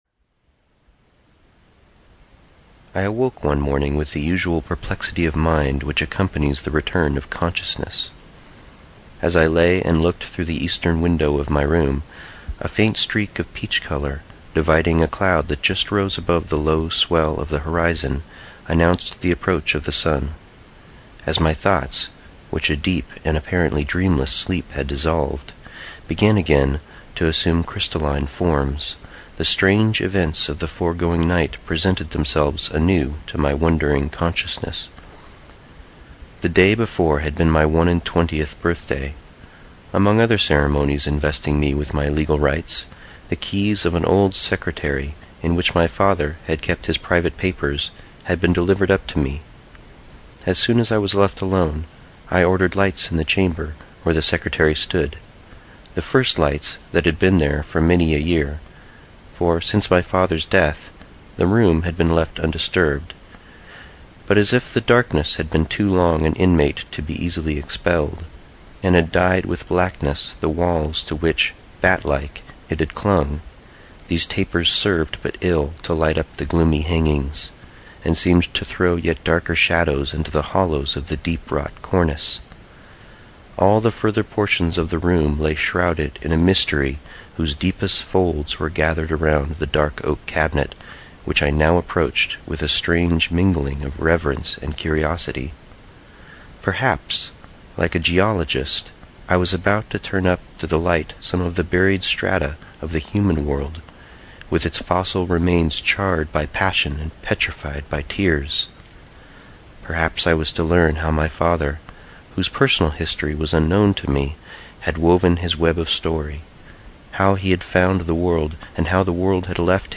Phantastes (EN) audiokniha
Ukázka z knihy